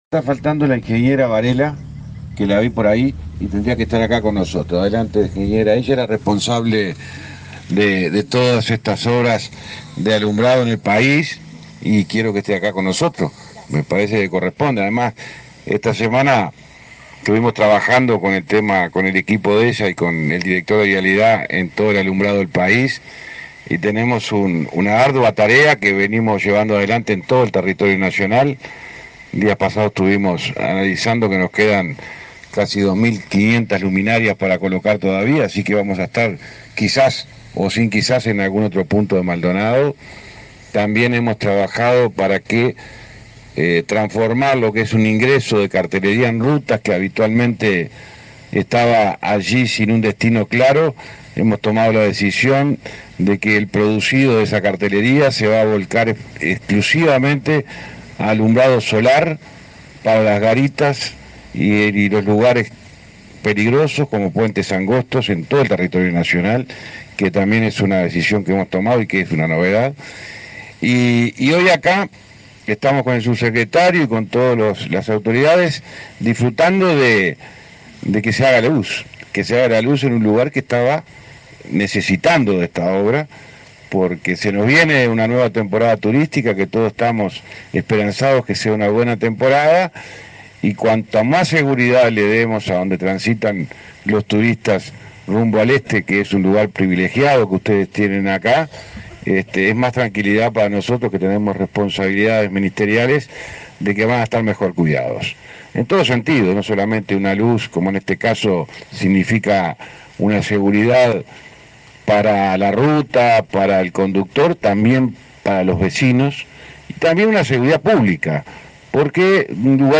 Palabras del ministro de Transporte, José Luis Falero
El ministro José Luis Falero participó en la disertación en el evento.